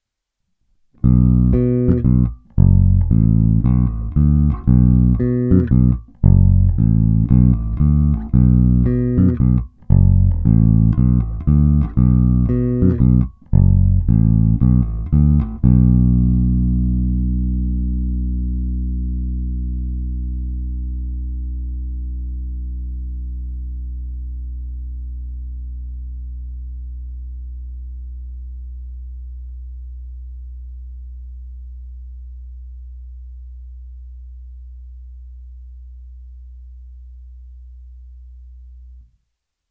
Plnotučný zvuk s pěknými středy.
Není-li uvedeno jinak, následující nahrávky jsou provedeny rovnou do zvukové karty a s plně otevřenou tónovou clonou. Nahrávky jsou jen normalizovány, jinak ponechány bez úprav.
Oba snímače sériově